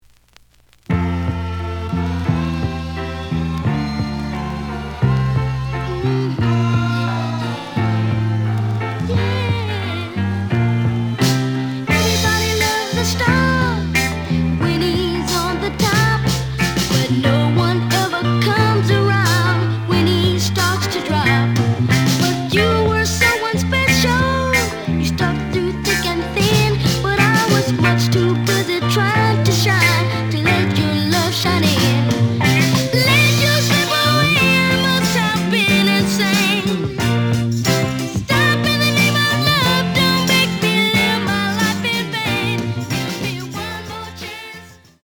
The audio sample is recorded from the actual item.
●Genre: Soul, 70's Soul
Some noise on later half of B side due to stains.